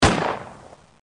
手枪开枪.mp3